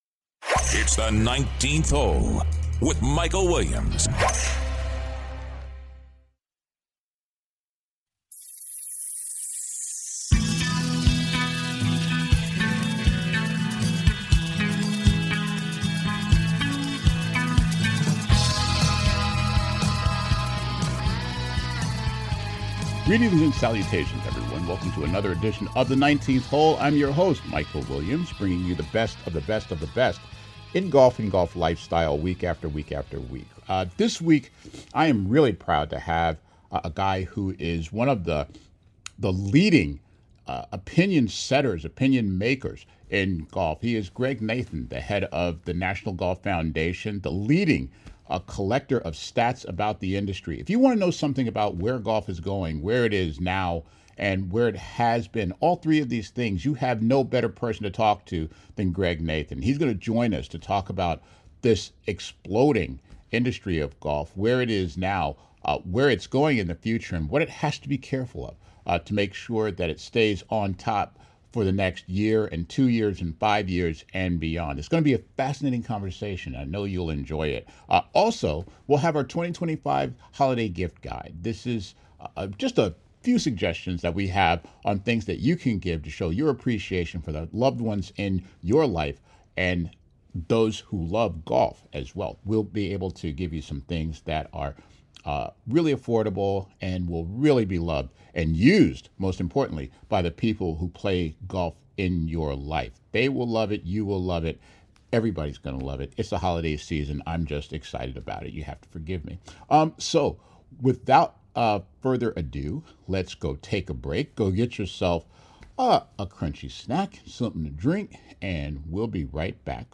an exclusive one-on-one interview